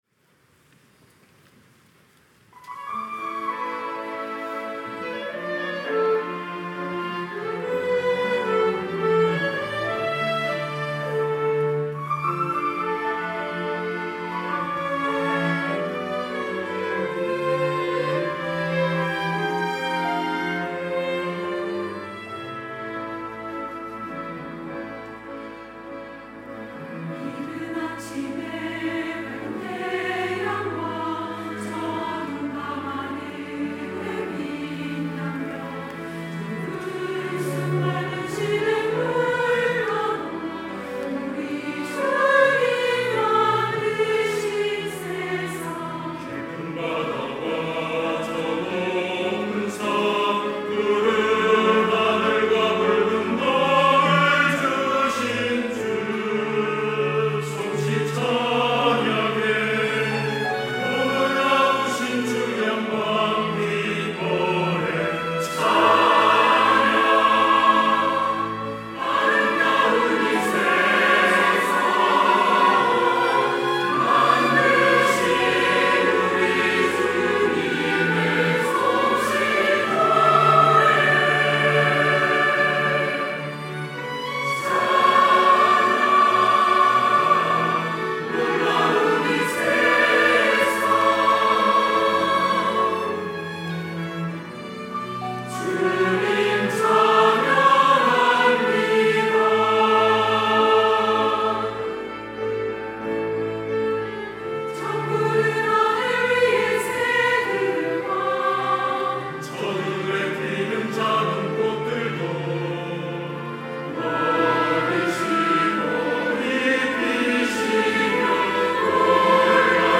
호산나(주일3부) - 주님 만드신 세상
찬양대